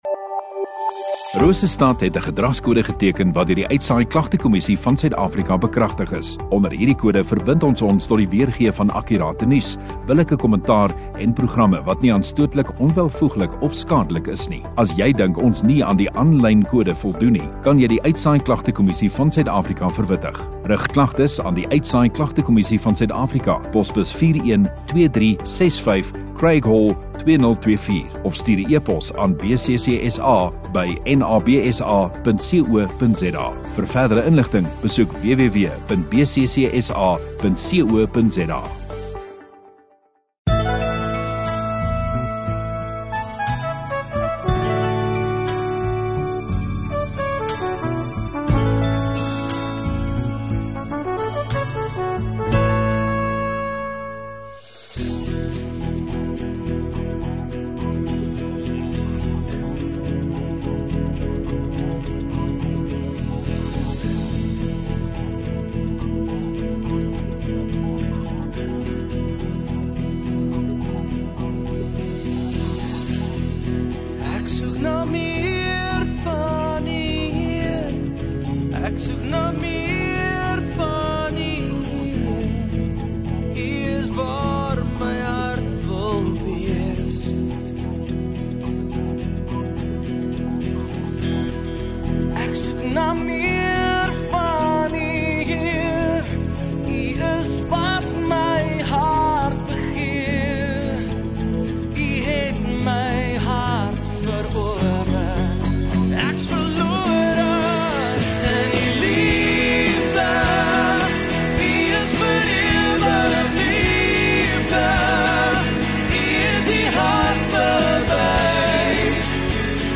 15 Sep Sondagaand Erediens